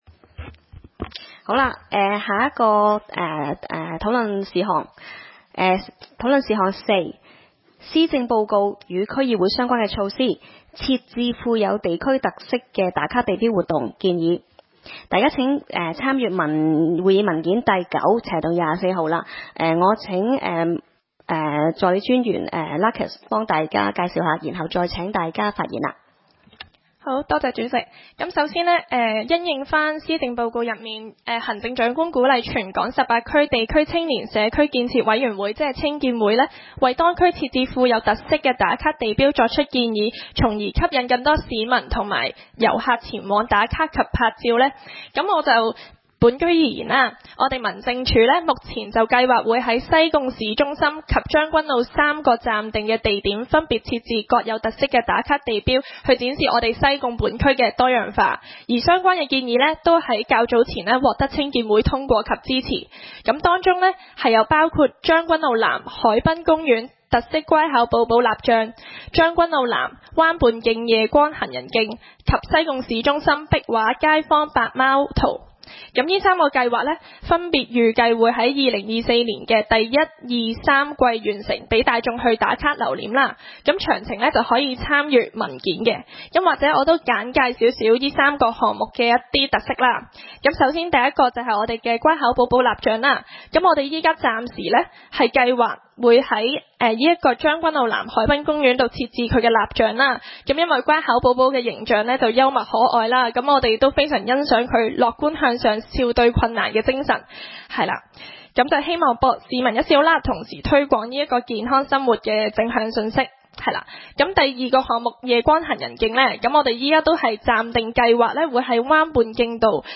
西貢區議會 - 區議會大會的錄音記錄
會議的錄音記錄